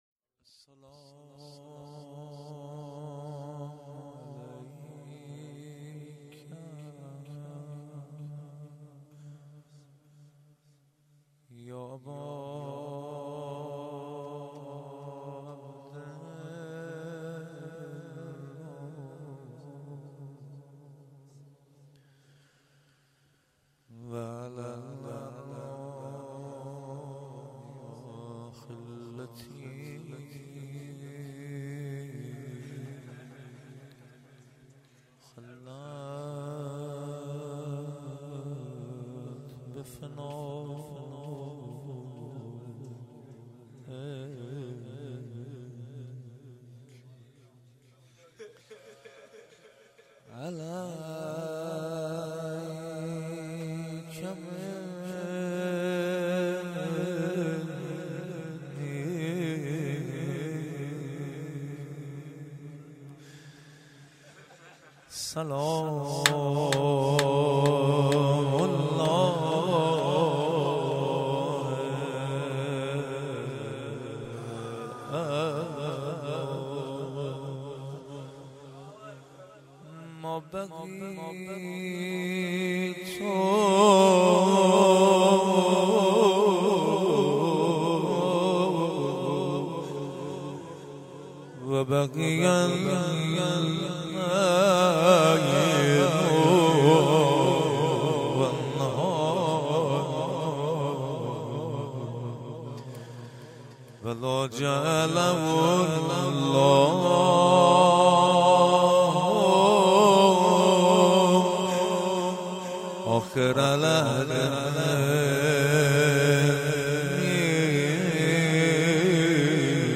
زمزمه و روضه پایانی